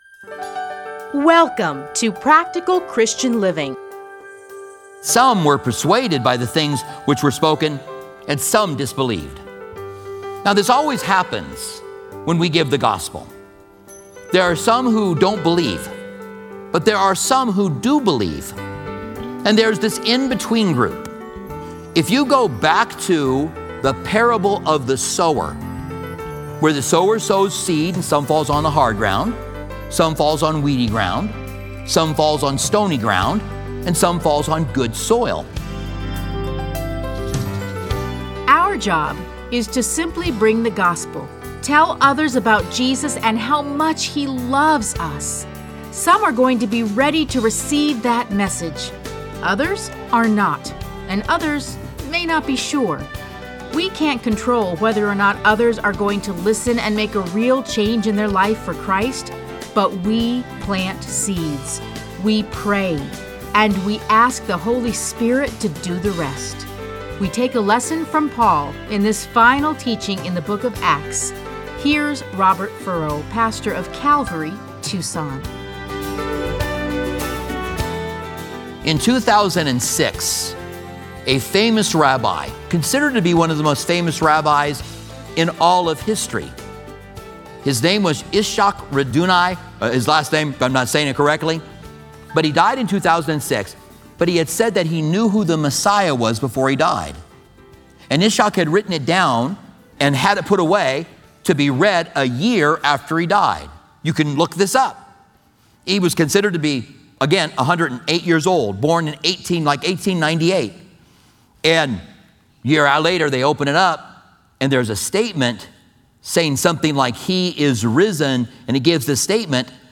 Listen to a teaching from Acts 28:1-31.